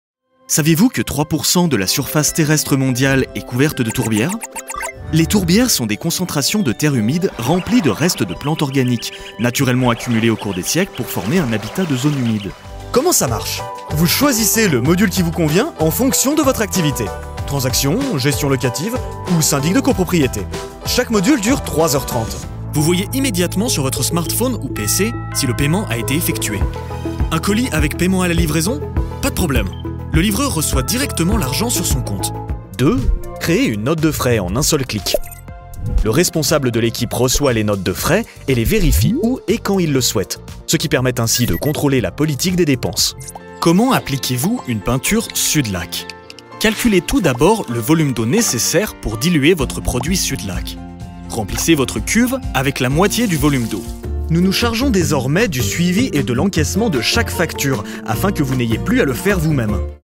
Natural, Versátil, Seguro, Amable, Empresarial
Explicador
Su voz natural, de tono medio-grave, suena profesional y confiable, pero también cálida y amigable, ideal para contenido corporativo y educativo.